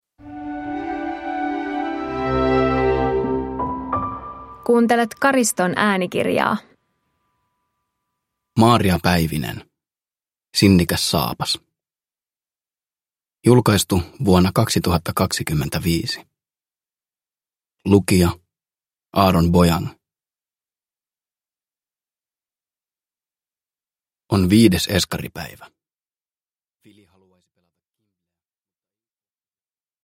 Sinnikäs saapas – Ljudbok